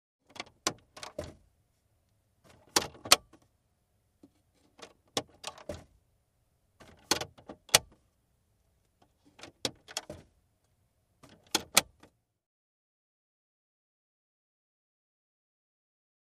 Glove Compartment | Sneak On The Lot
Glove Compartment Door Open And Close, Metal Clicks And Knob Turns, Close Perspective.